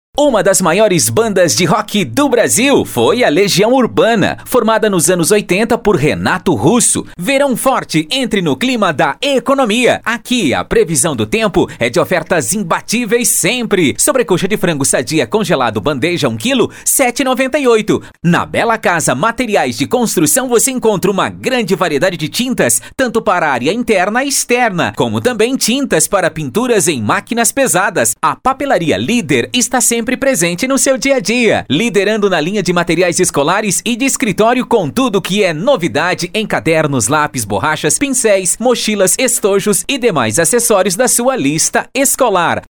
VOZES MASCULINAS
Estilos: Animada